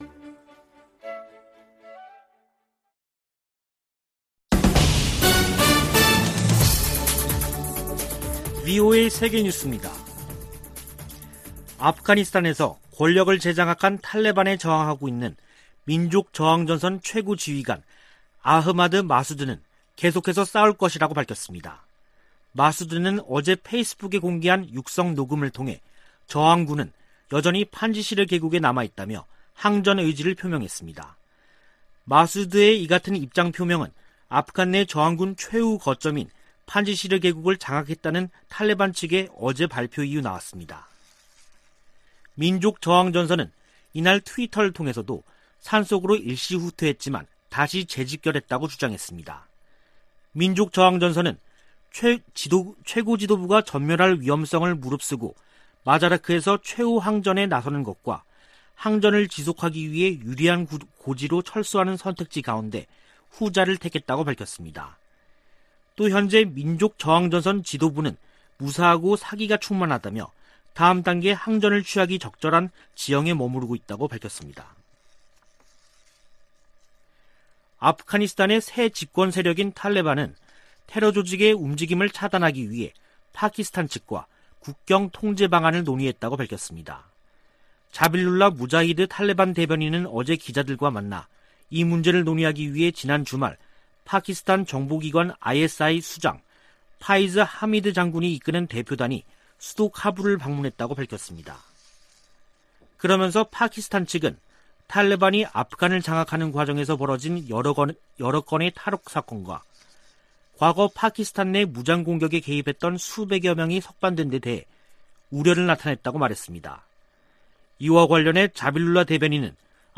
VOA 한국어 간판 뉴스 프로그램 '뉴스 투데이', 2021년 9월 7일 2부 방송입니다. 북한이 핵무기와 미사일 관련 국제 규칙을 노골적으로 무시하고 있다고 북대서양조약기구(NATOㆍ나토) 사무총장이 밝혔습니다. 오는 14일 개막하는 제 76차 유엔총회에서도 북한 핵 문제가 주요 안건으로 다뤄질 전망입니다. 아프가니스탄을 장악한 탈레반이 미군 무기를 북한에 판매하지 않을 것이라고 밝혔습니다.